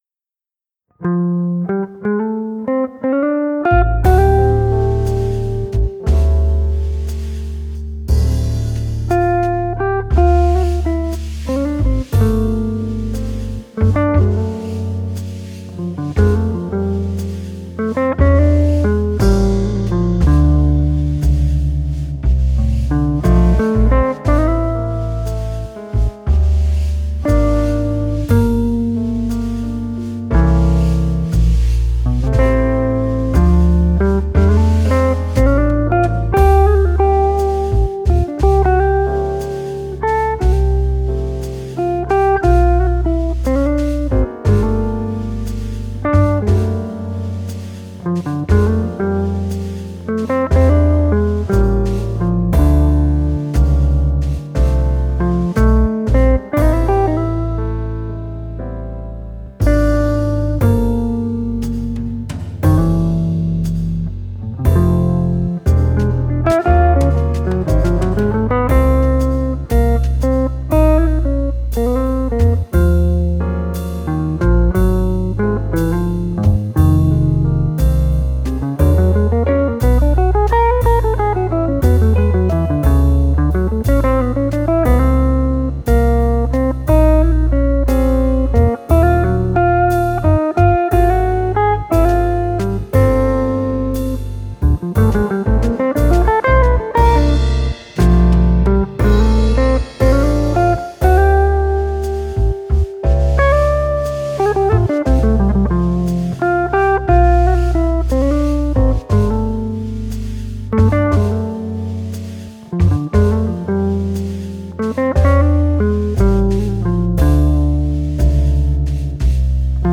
nice fills.